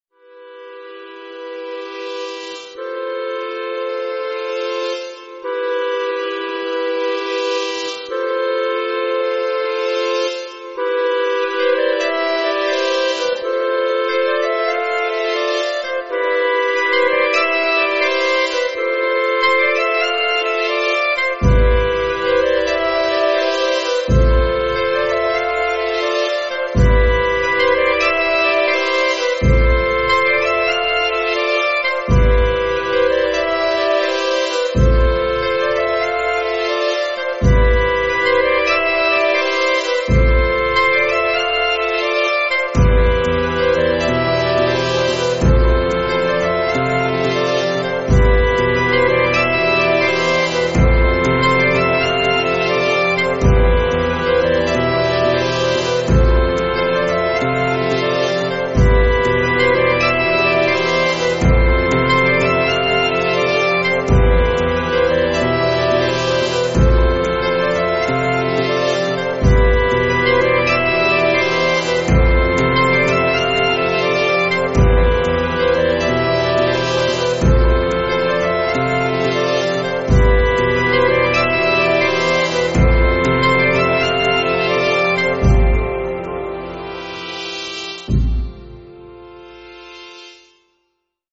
Estilo: Orquesta